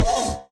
Sound / Minecraft / mob / horse / skeleton / hit2.ogg